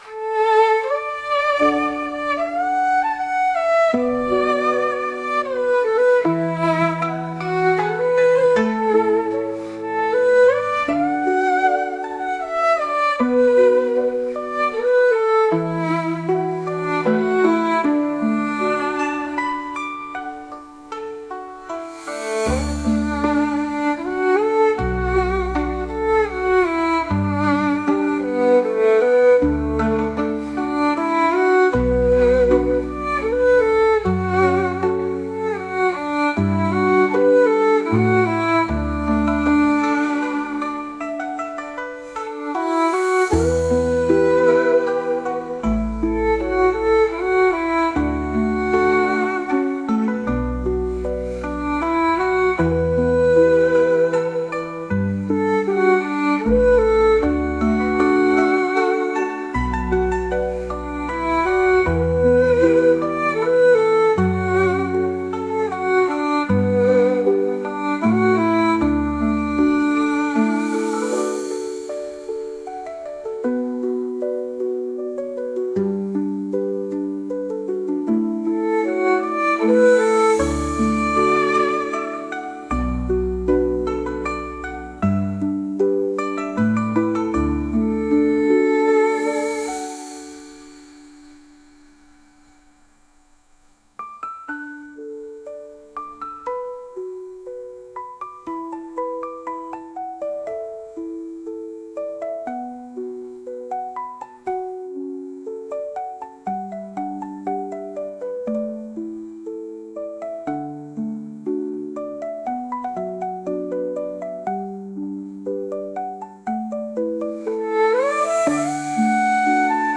Мотылек в ночи Релакс Китайская музыка релакс